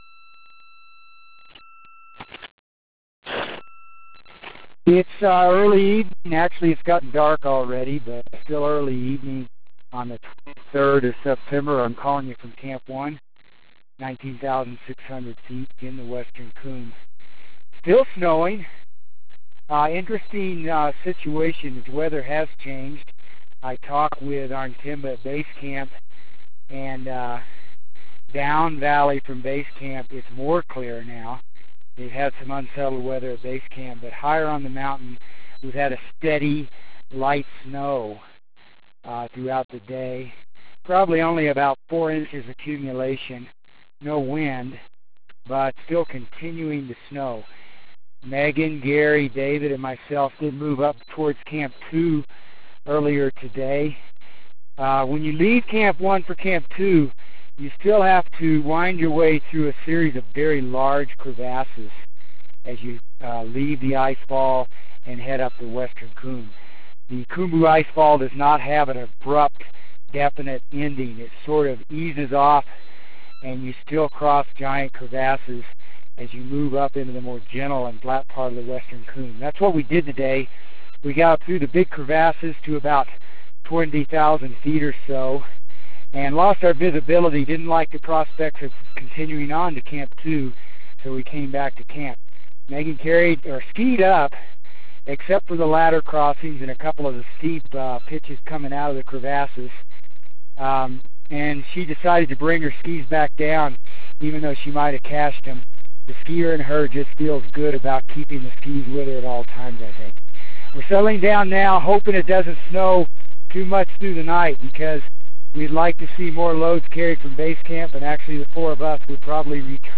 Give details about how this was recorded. September 23 - Exploring above Camp 1